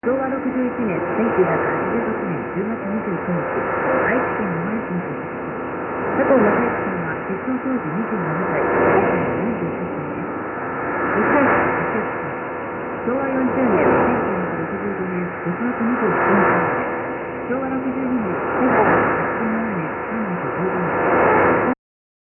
6/28の第一放送受信録音、韓国（ソウル）日本（九州）です。
ソウルではかなり良好な受信状態です。